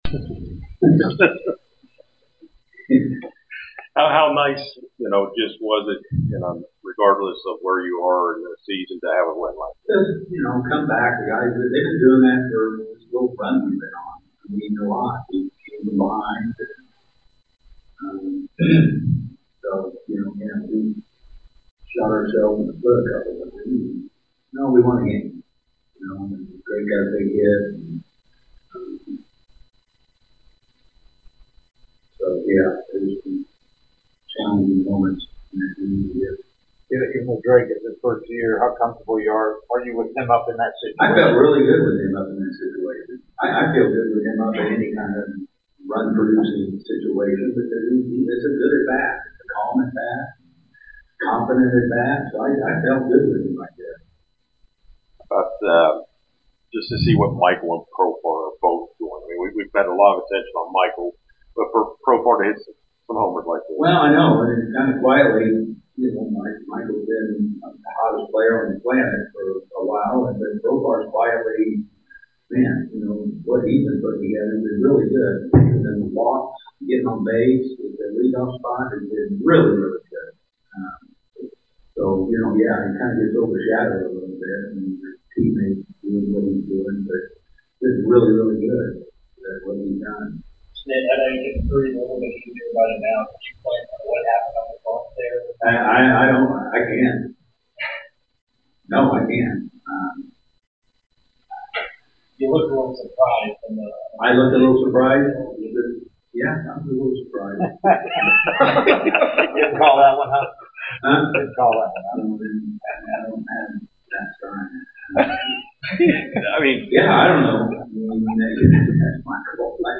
08-19-25 Atlanta Braves Manager Brian Snitker Postgame Interview
Atlanta Braves Manager Brian Snitker Postgame Interview after defeating the Chicago White Sox at Truist Park.